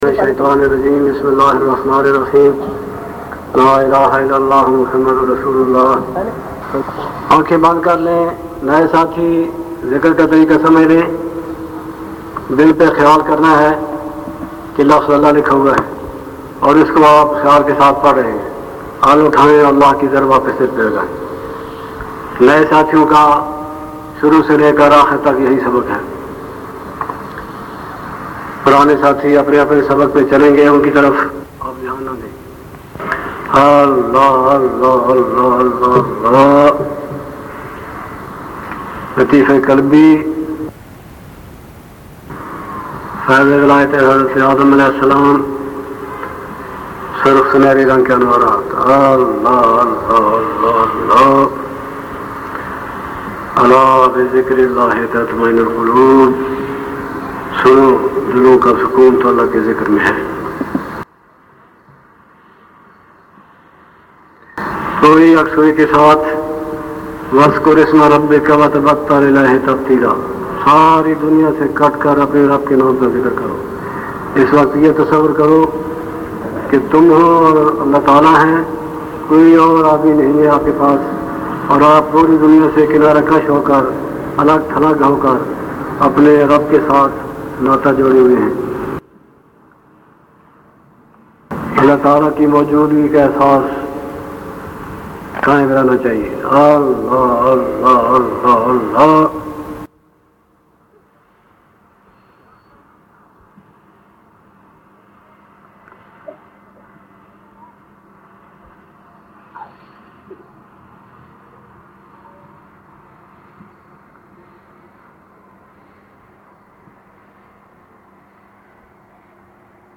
Mehfil e Zikr O Muraqba is held on every sunday from 1:10pm to 1:45pm at Dar ul Faizan Pinwal Shareef Chakwal.